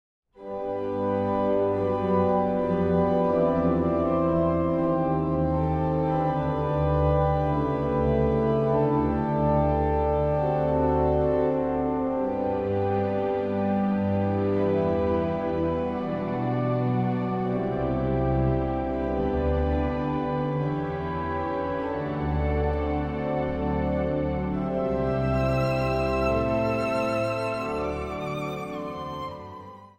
Instrumentaal | Dwarsfluit
Instrumentaal | Harp
Instrumentaal | Hobo
Instrumentaal | Klarinet
Instrumentaal | Marimba
Instrumentaal | Panfluit
Instrumentaal | Synthesizer
Instrumentaal | Trompet
Instrumentaal | Viool